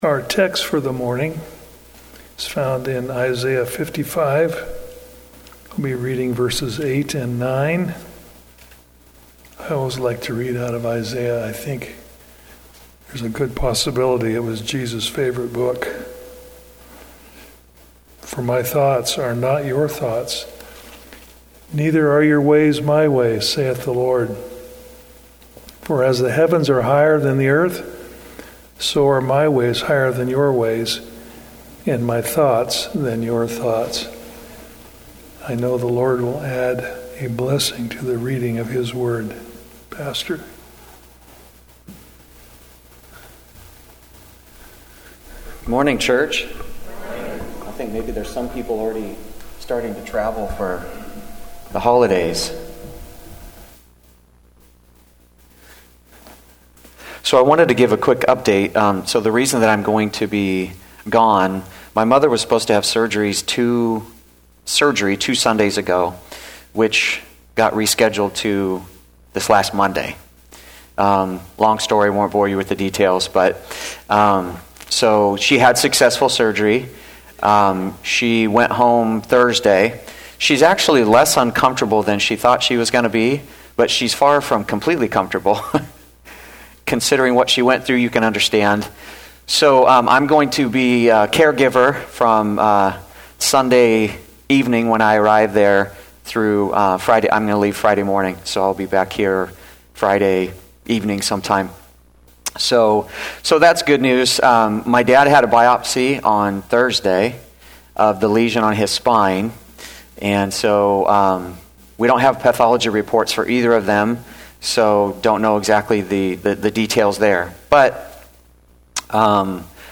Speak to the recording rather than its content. Seventh-day Adventist Church, Sutherlin Oregon